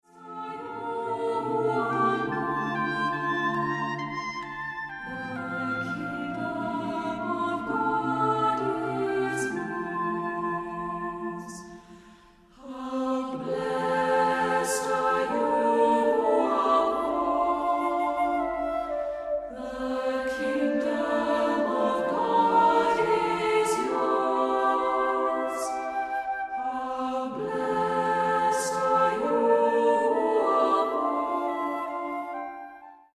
• Sachgebiet: Klassik: Geistliche Chormusik